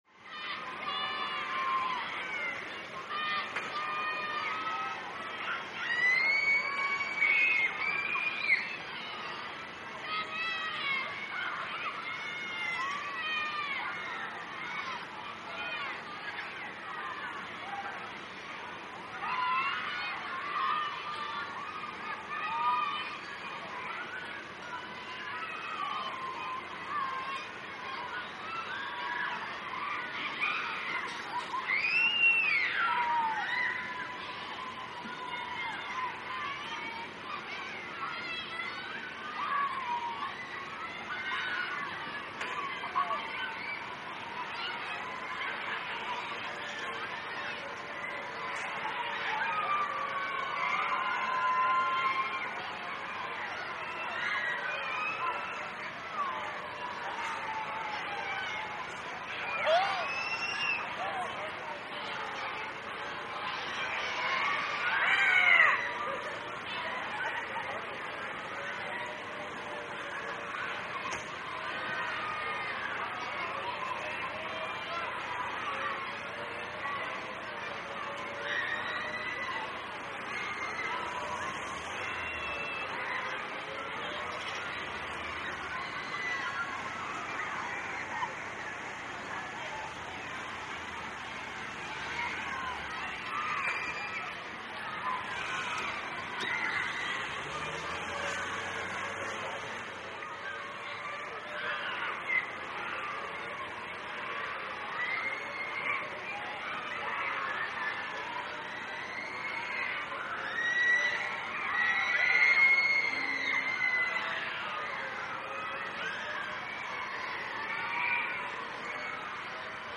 Ride; Carnival Ride Ambience With Distant Passenger Screams And Machinery.